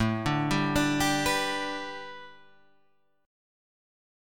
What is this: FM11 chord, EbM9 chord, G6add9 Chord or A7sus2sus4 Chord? A7sus2sus4 Chord